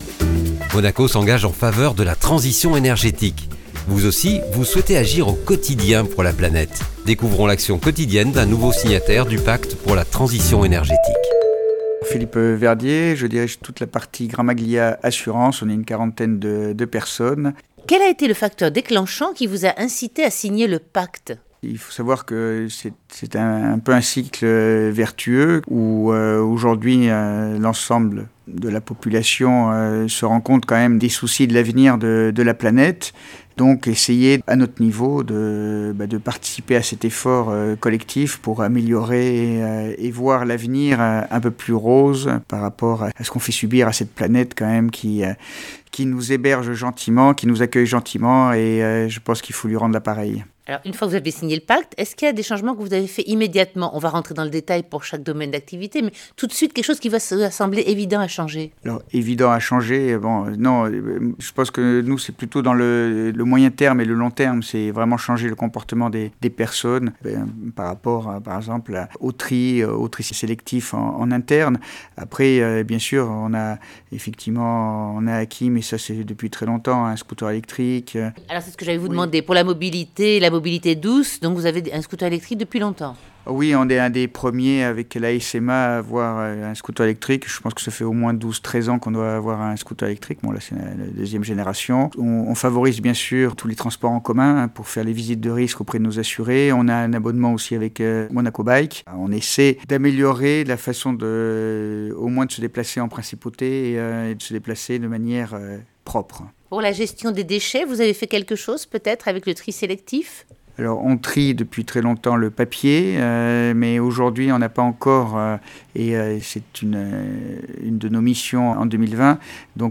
interview_mte_2020_gramaglia_assurances.mp3